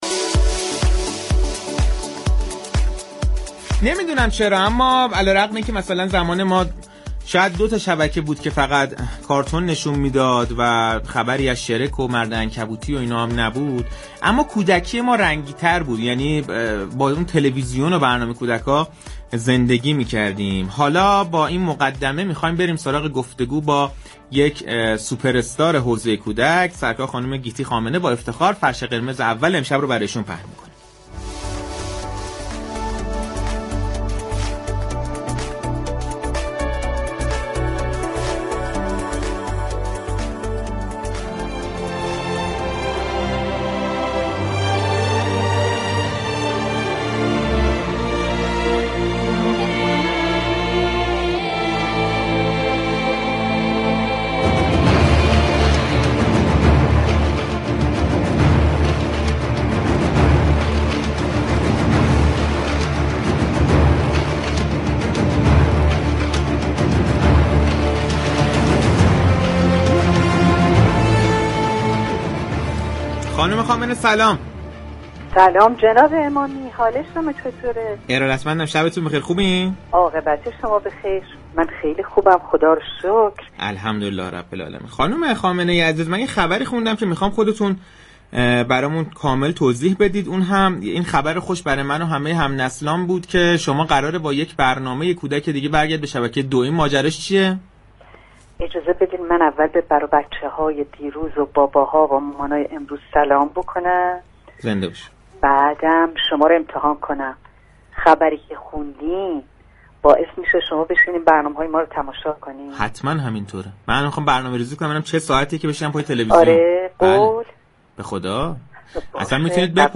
گیتی خامنه، گوینده و مجری برجسته كشور در گفتگو با برنامه پشت صحنه رادیو تهران از پخش برنامه جدید خود با عنوان